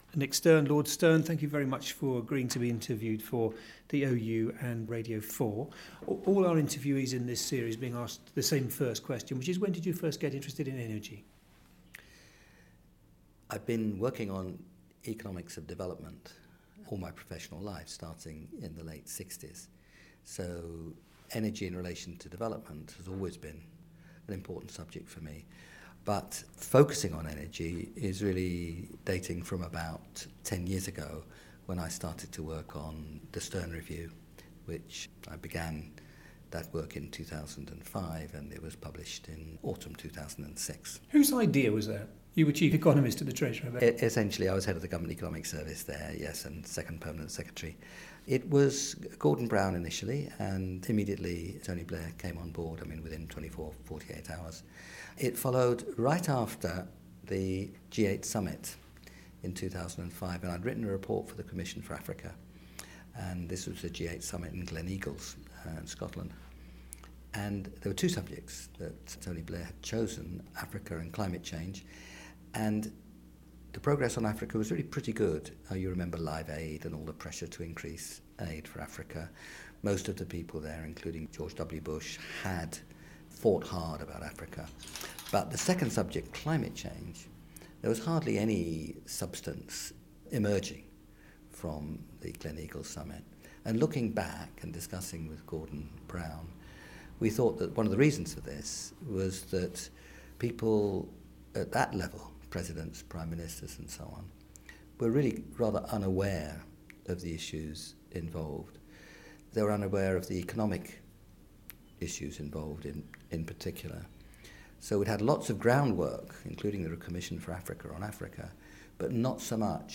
Interview with Nicholas Stern by Roger Harrabin